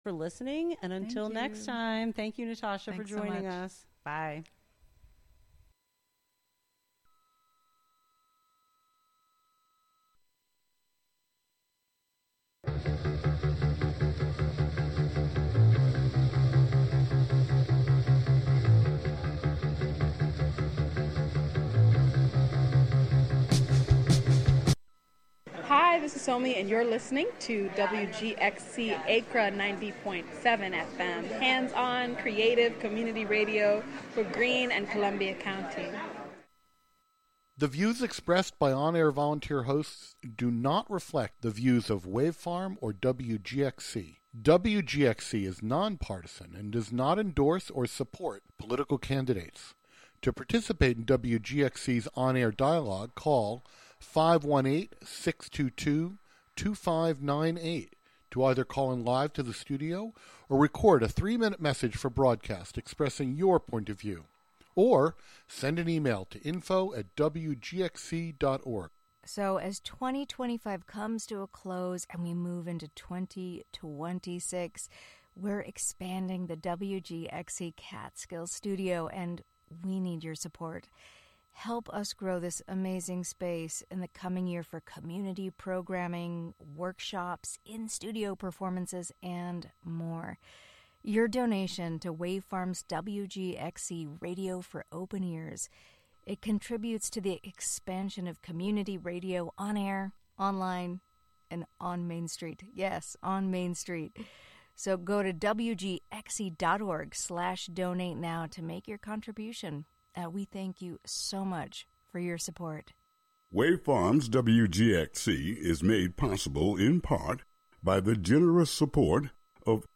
Hosted by Greater Hudson Promise Neighborhood youths, "The Promise Cool Kidz" is a half hour of talk, rap battles, songs, and more broadcasting live from GHPN!